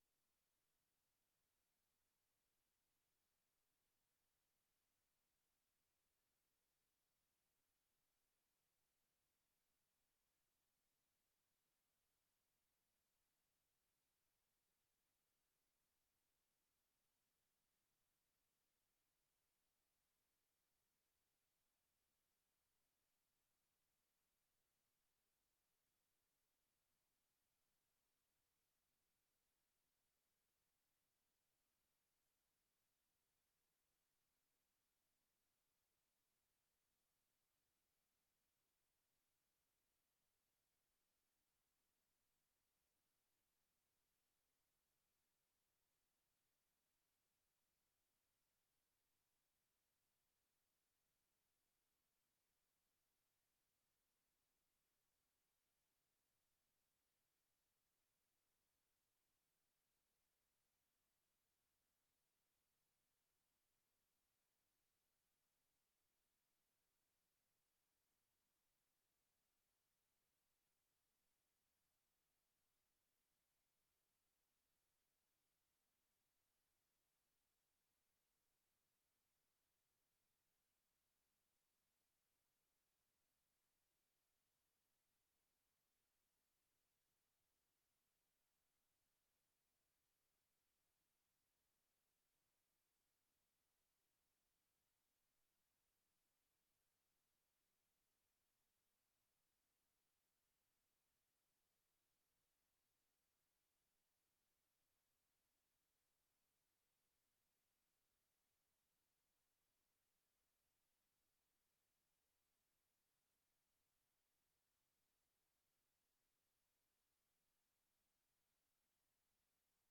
Sunday Service - Part 10